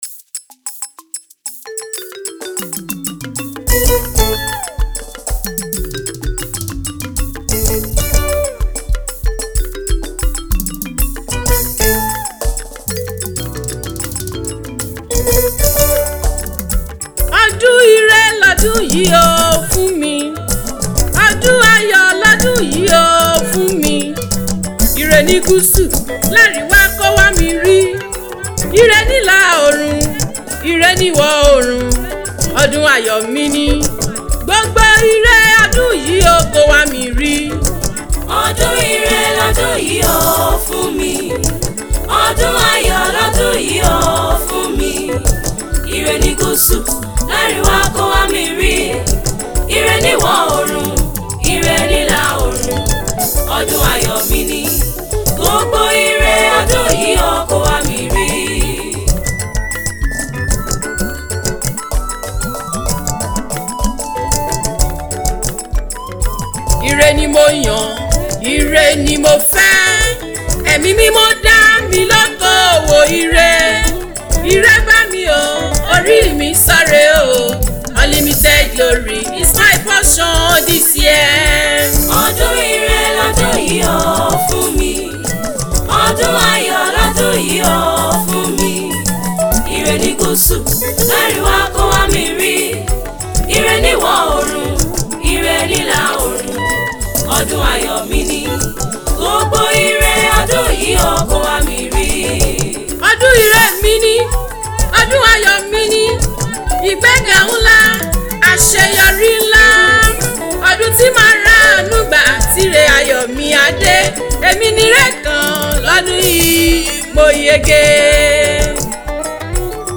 powerful and prophetic new year song